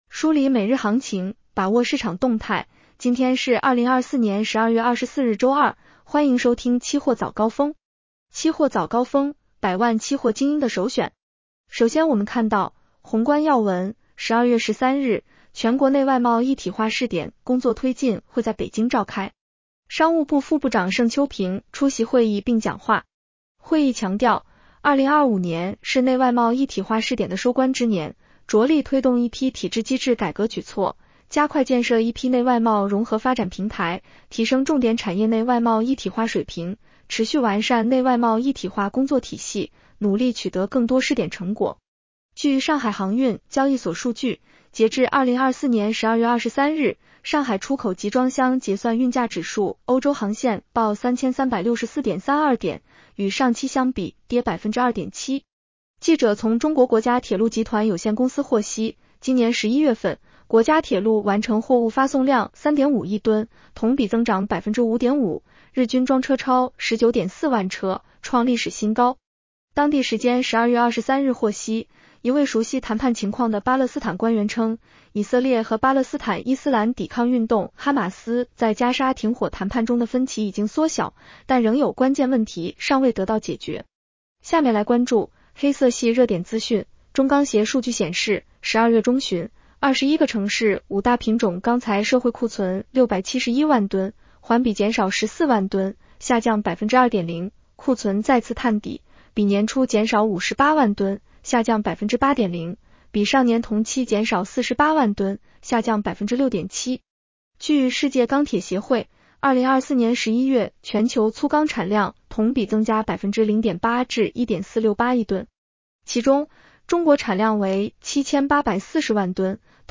期货早高峰-音频版 女声普通话版 下载mp3 宏观要闻 1. 12月13日，全国内外贸一体化试点工作推进会在北京召开。